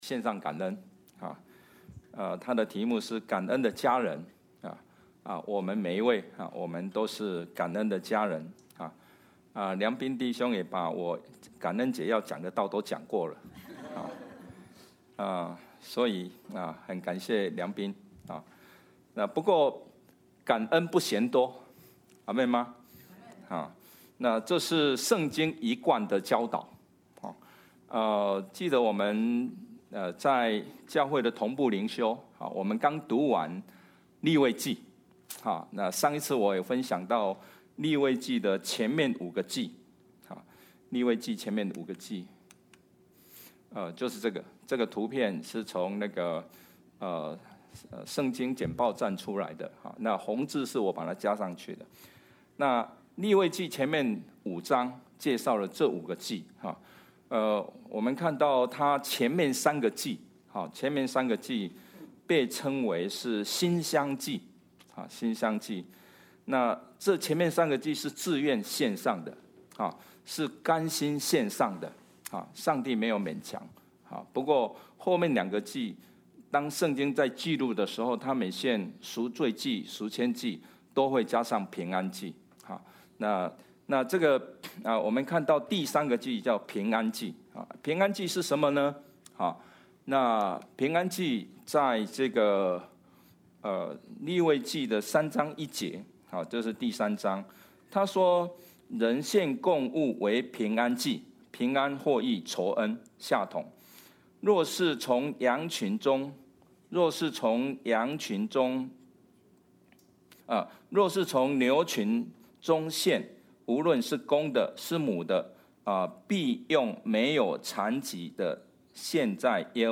Passage: Luke 17:11–19 Service Type: 主日证道 Download Files Notes « 感恩的家人 感恩神助 » Submit a Comment Cancel reply Your email address will not be published.